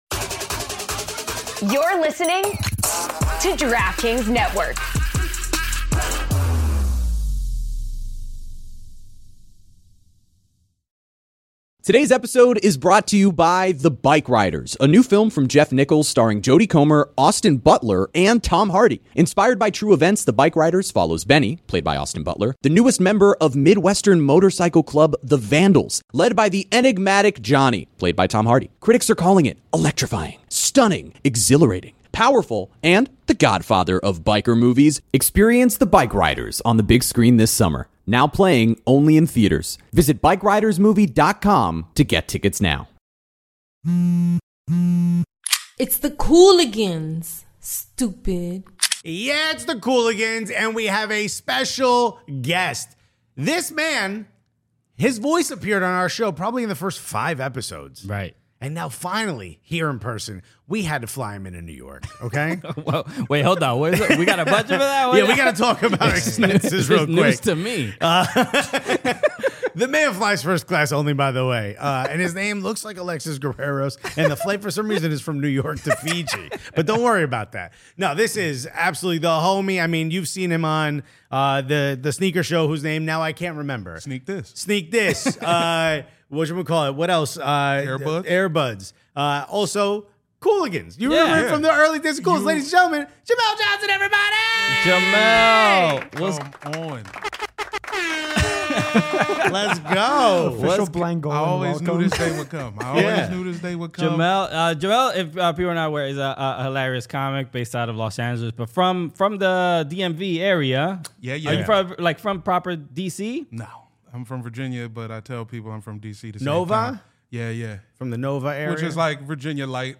in studio to make his second Cooligans appearance! We break down the pathetically heartwarming Tom Holland interview in which he shares all the pain Spurs have caused him and talk about Lionel Messi recruiting his former teammate, Sergio Busquets, to MLS.